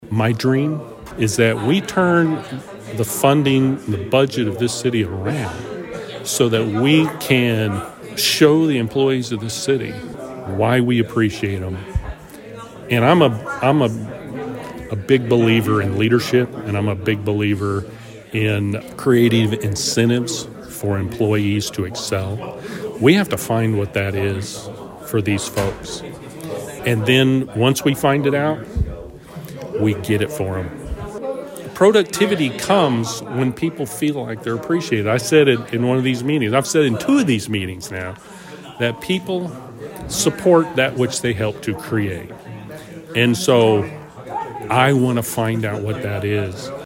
He says that he aspires to turn the city budget around in order to help employees excel.